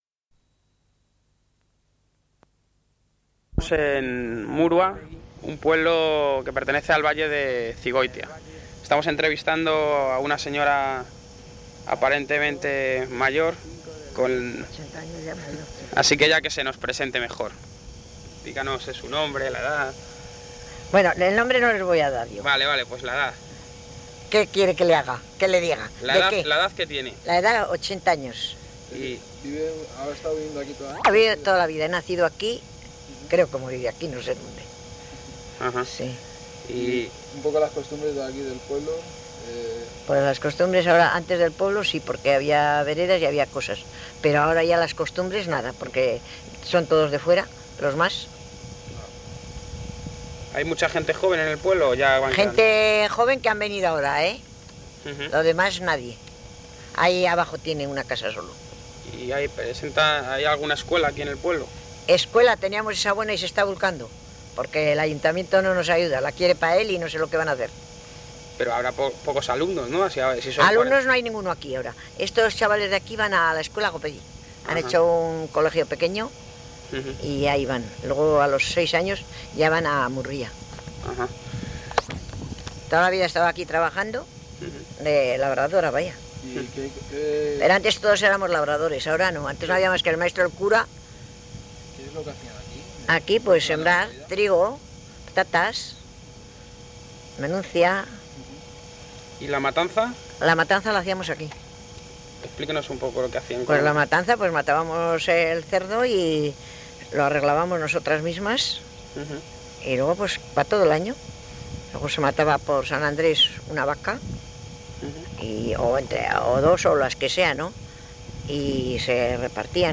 Locality Murua (Zigoitia)